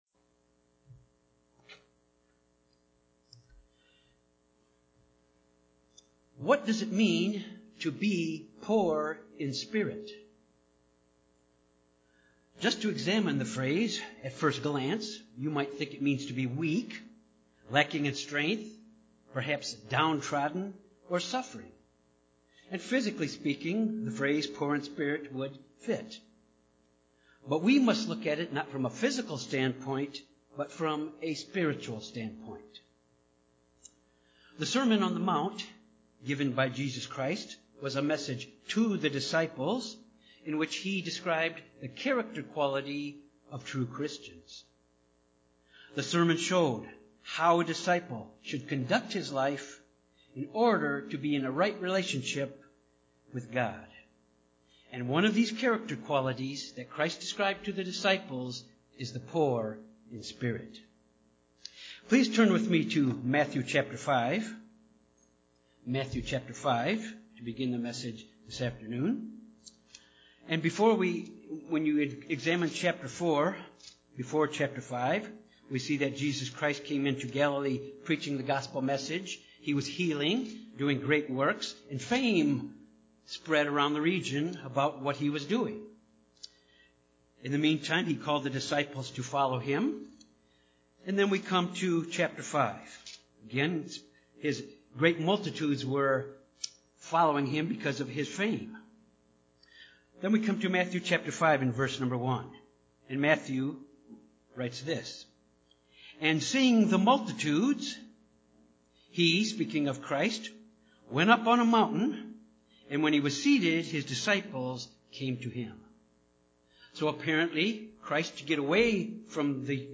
Sermons
Given in Jonesboro, AR Little Rock, AR